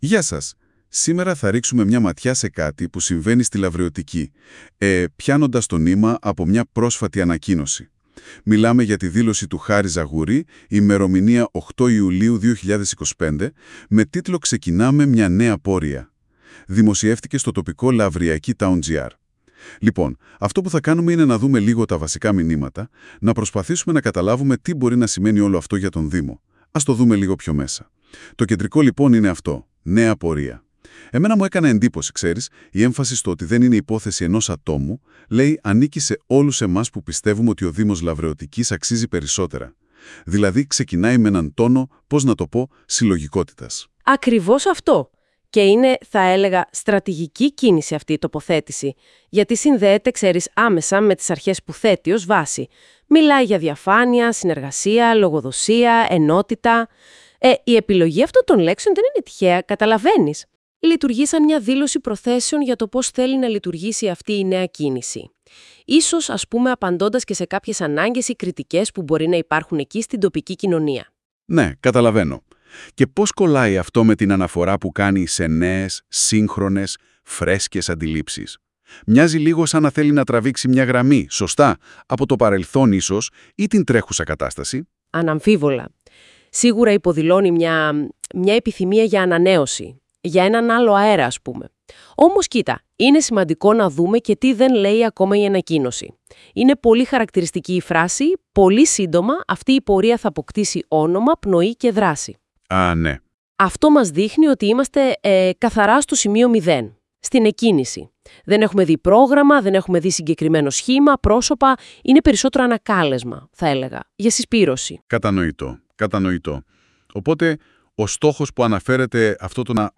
Ακούστε πως σχολίασε η Α.Ι. της Google με μορφή podcast